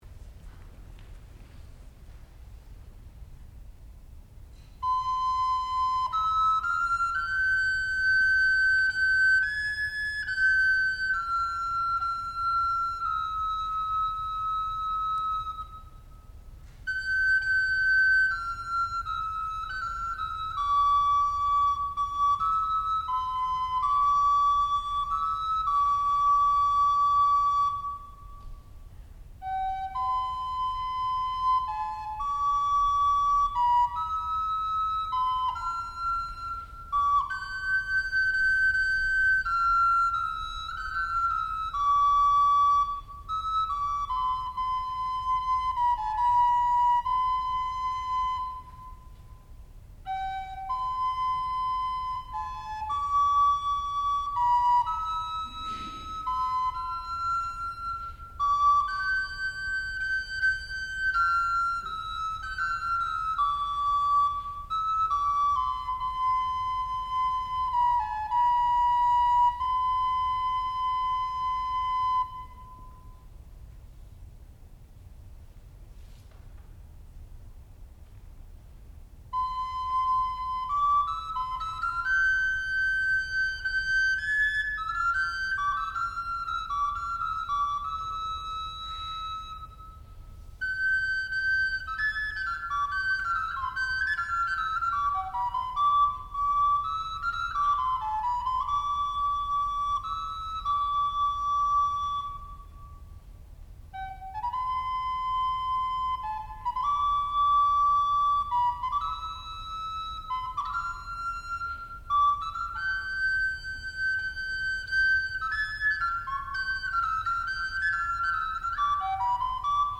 sound recording-musical
classical music
recorder